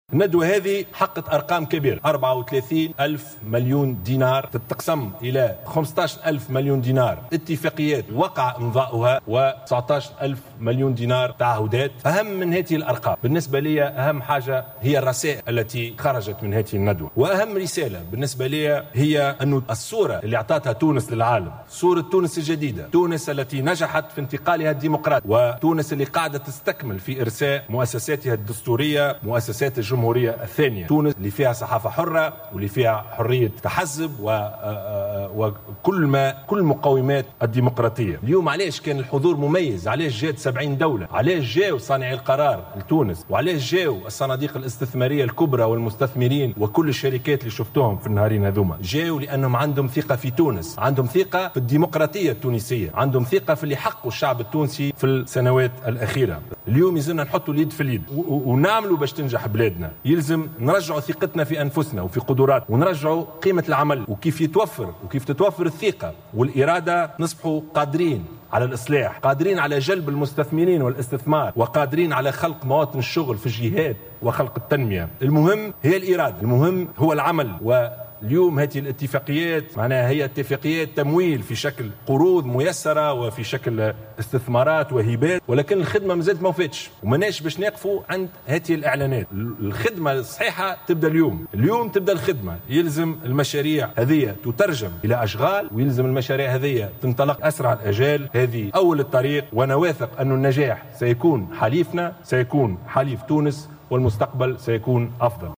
أعلن رئيس الحكومة يوسف الشاهد في اختتام الندوة الدولية للاستثمار "تونس 2020" مساء اليوم الأربعاء 30 نوفمبر 2016 أن تونس تمكنت من تعبئة 34 ألف مليون دينار.